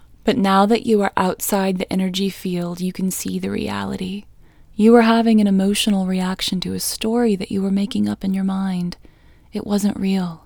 OUT Technique Female English 31